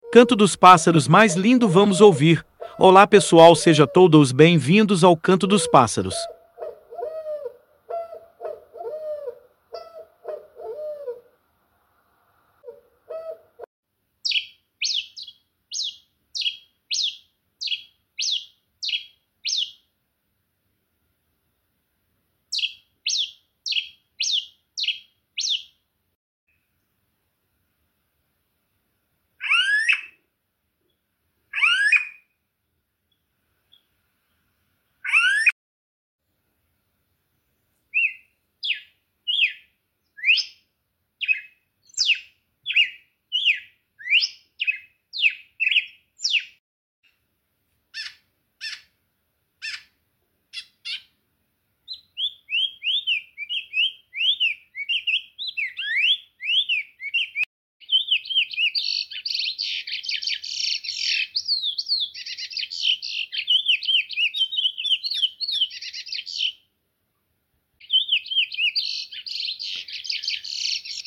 canto dos pássaros mais lindo sound effects free download